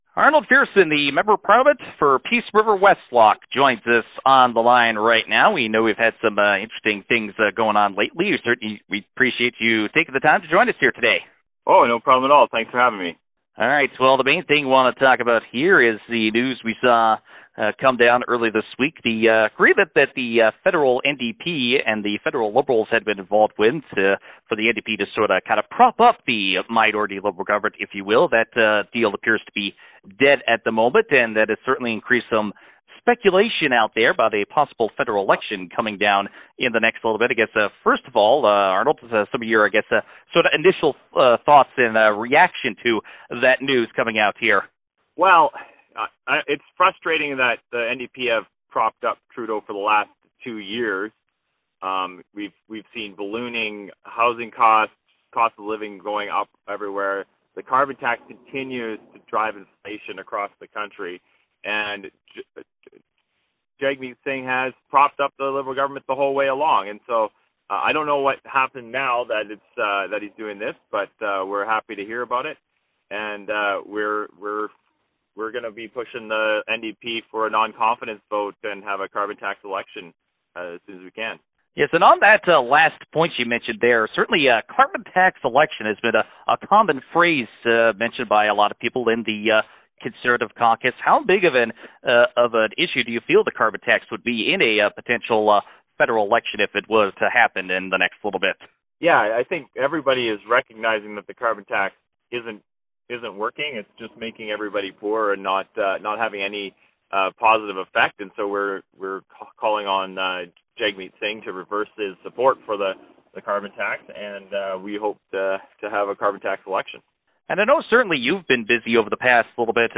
viersen-interview-september-5.wav